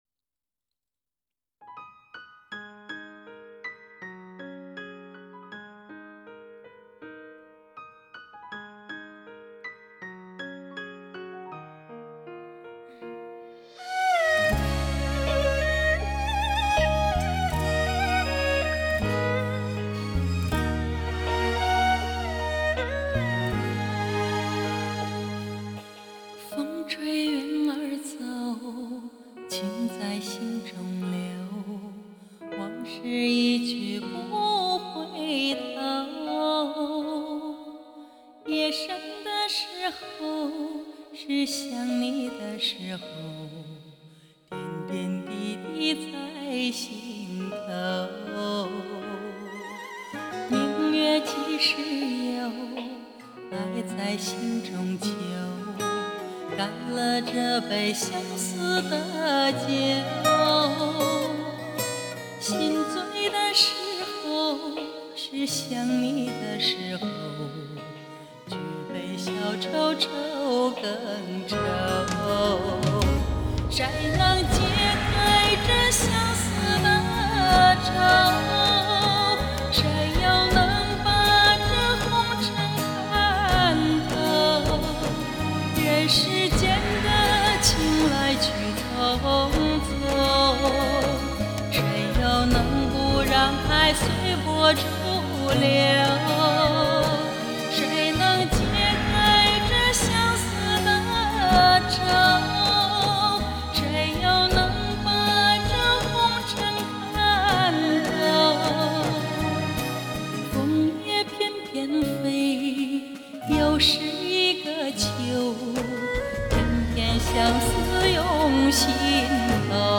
听到了吗？落叶、露珠、晨雾、细雨......就在周围。
路虎揽胜1700音响试听.mp3